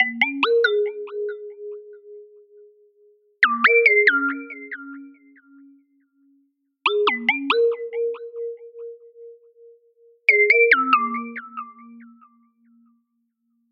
Tag: 140 bpm Electronic Loops Synth Loops 2.31 MB wav Key : Unknown